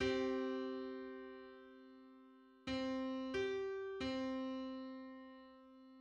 File:Seven-hundred-fifty-ninth harmonic on C.mid - Wikimedia Commons
Public domain Public domain false false This media depicts a musical interval outside of a specific musical context.
Seven-hundred-fifty-ninth_harmonic_on_C.mid.mp3